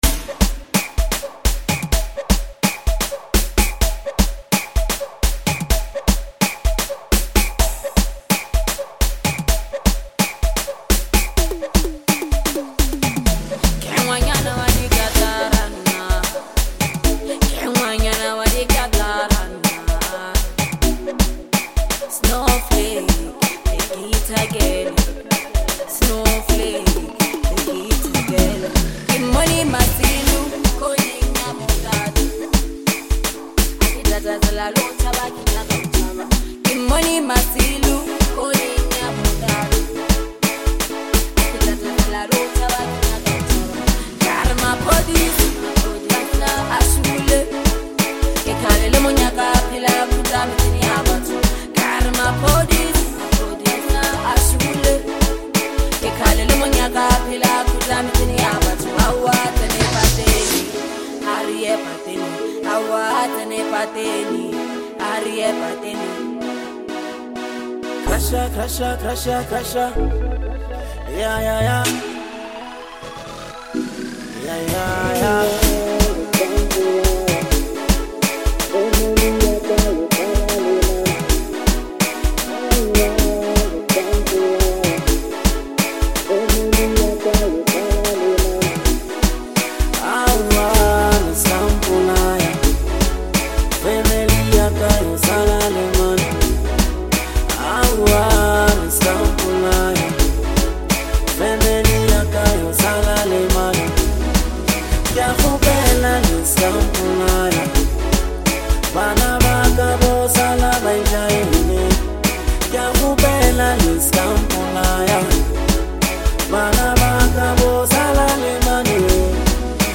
Lekompo reigning stars